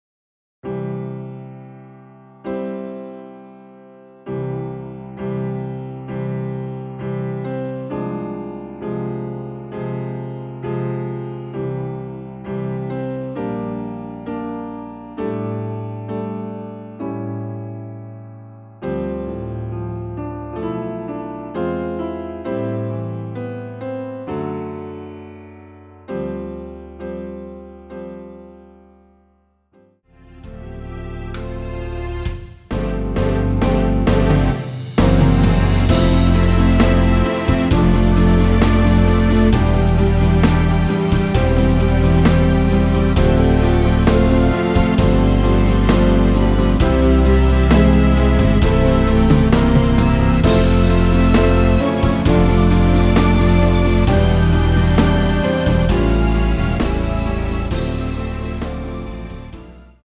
전주 없는곡이라 전주 1마디 만들어 놓았습니다.(미리듣기 참조)
◈ 곡명 옆 (-1)은 반음 내림, (+1)은 반음 올림 입니다.
앞부분30초, 뒷부분30초씩 편집해서 올려 드리고 있습니다.